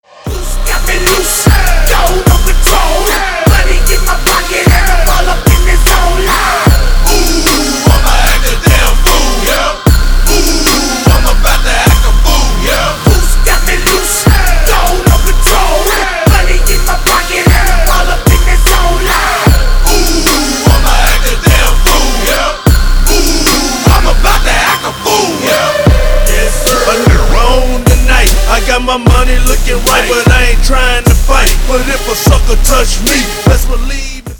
Громкие звонки, звучные рингтоны